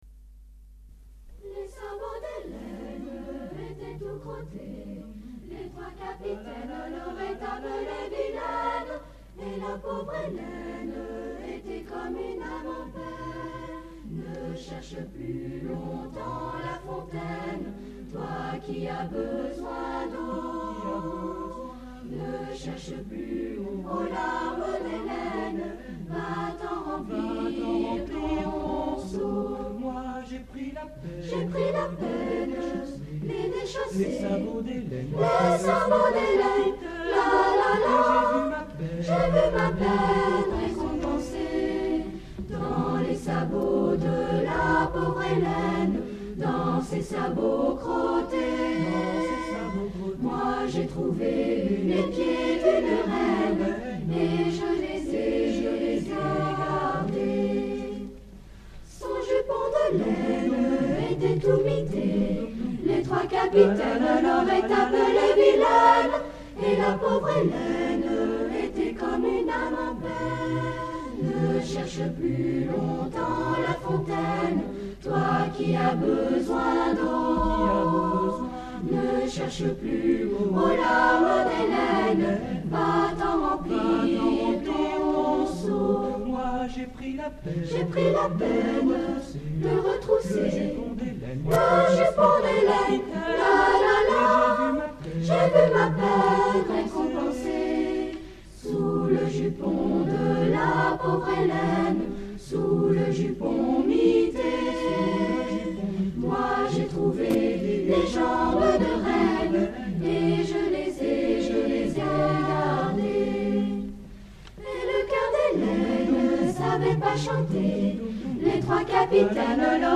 Concert  ACJ Macon CAC 20h30 27 Mai 1988 MACON